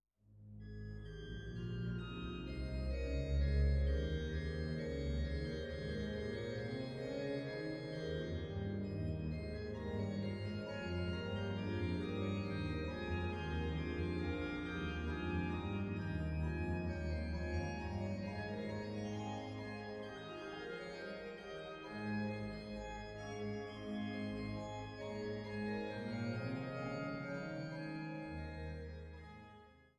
Deshalb wurde diese Werkgruppe für die vorliegende Einspielung an der Hildebrandt-Orgel in Naumburg ausgewählt, da diese eine Manualverteilung auf Haupt- und Oberwerk sowie auf dem Rückpositiv hat.